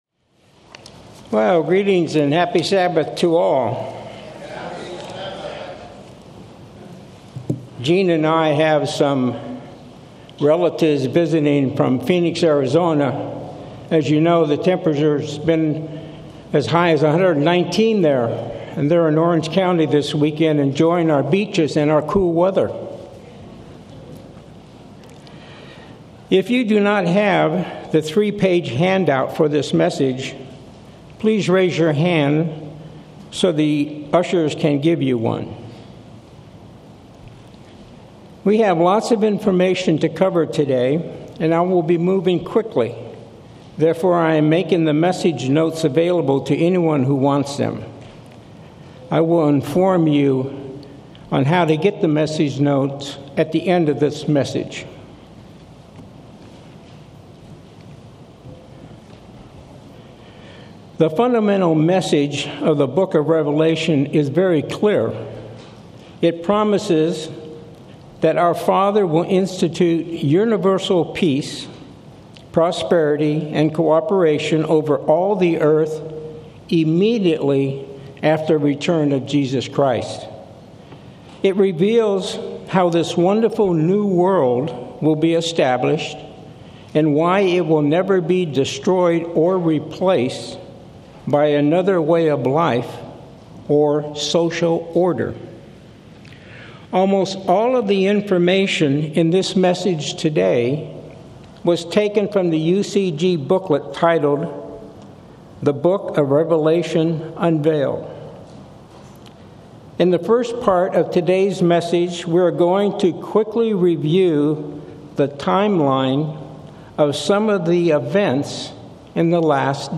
Sermons
Given in Orange County, CA